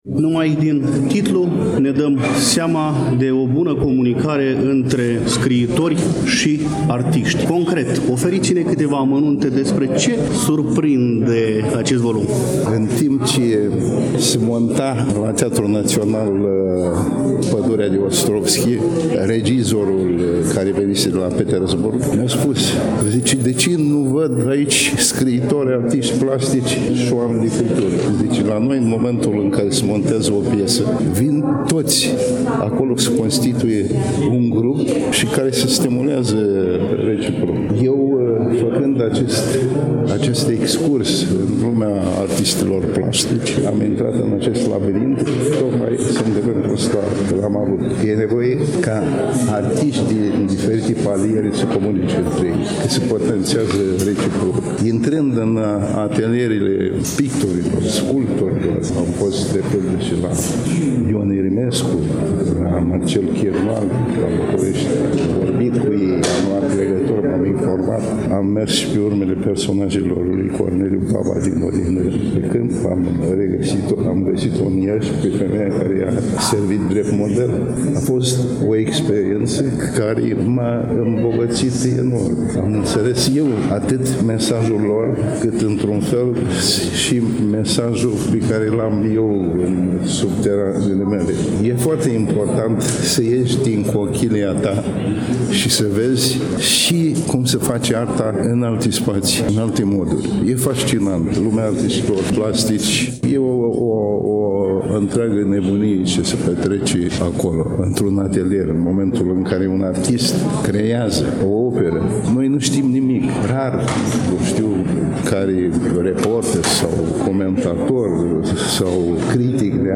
Manifestarea s-a desfășurat vineri, 5 aprilie 2024, începând cu ora 17 și 30 de minute, în incinta Galeriei de Artă „Th. Pallady” de pe strada Alexandru Lăpușneanu, Numerele 7-9, Iași.
Până a difuza interviul cu scriitorul Nichita Danilov, cel care ne povestește din culisele volumului Atelierele de pe Armeană, îi conturăm, succint, o portret biografic, dar amintim și principalele cărți pe care le-a publicat.